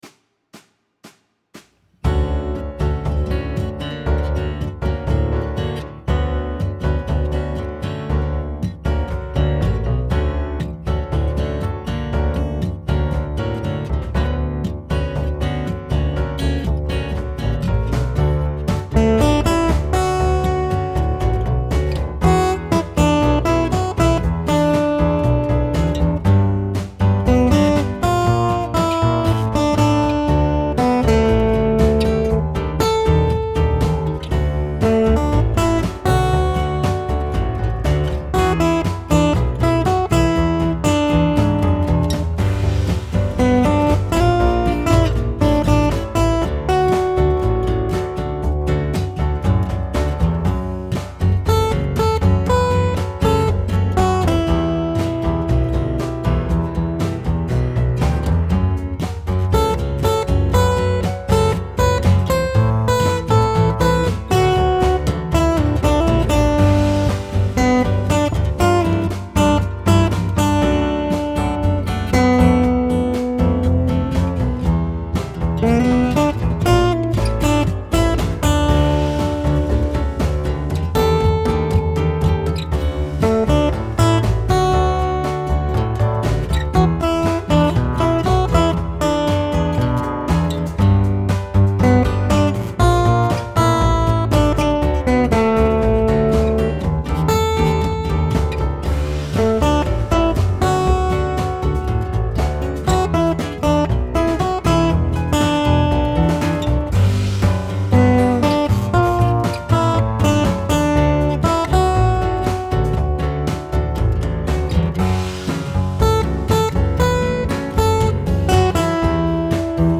JSB Musette guitar parts I and II